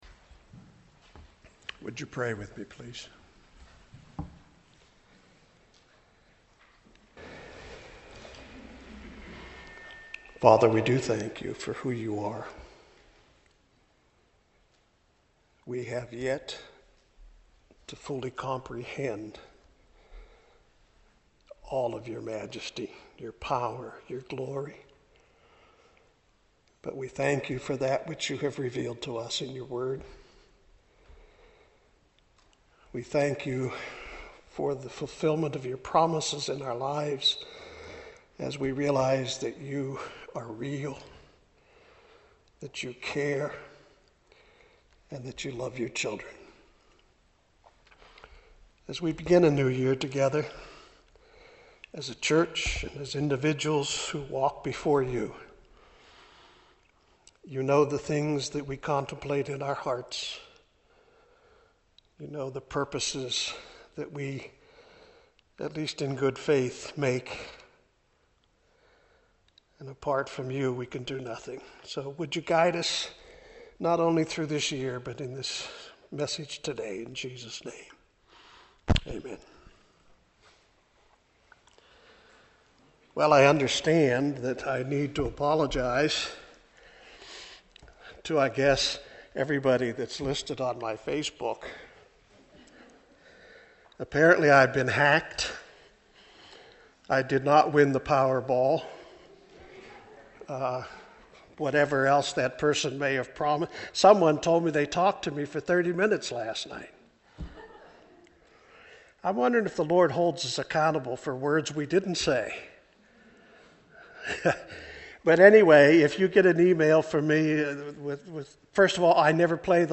Audio Sermons — Brick Lane Community Church
MP3 audio sermons from Brick Lane Community Church in Elverson, Pennsylvania.